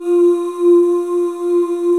Index of /90_sSampleCDs/Club-50 - Foundations Roland/VOX_xMaleOoz&Ahz/VOX_xMale Ooz 1S